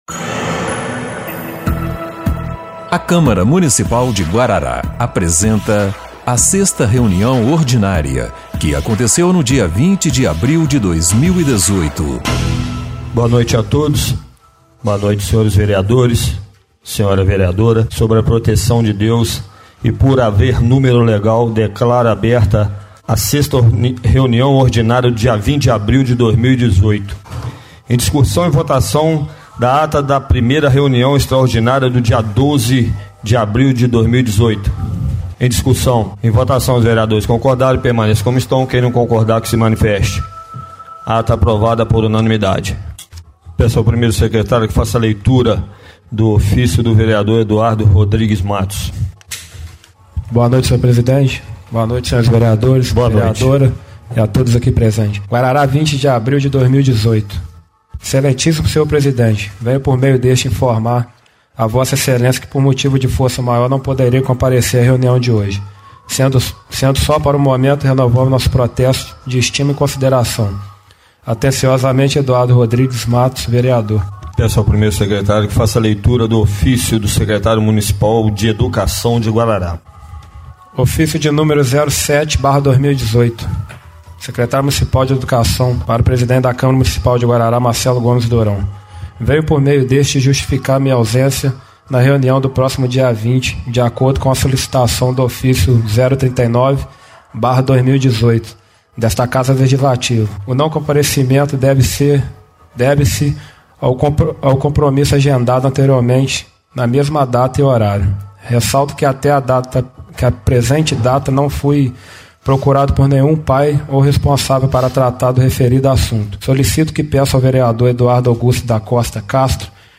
6ª Reunião Ordinária de 20/04/2018